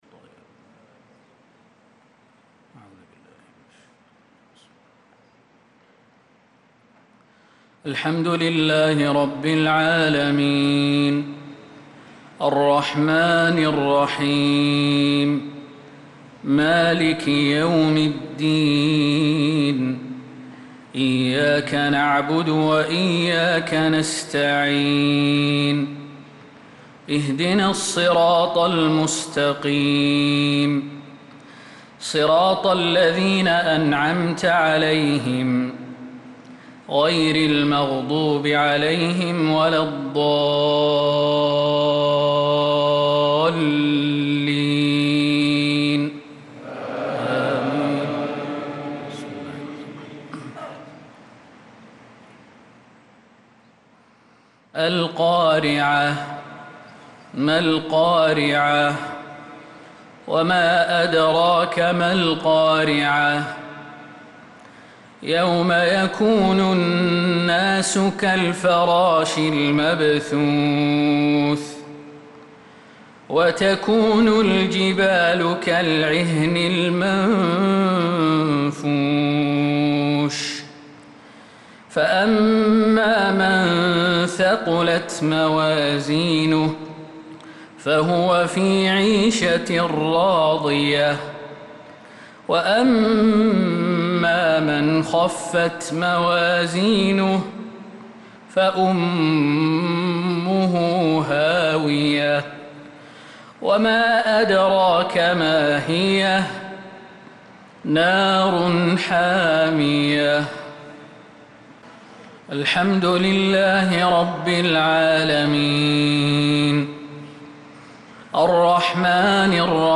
صلاة المغرب للقارئ خالد المهنا 16 محرم 1446 هـ
تِلَاوَات الْحَرَمَيْن .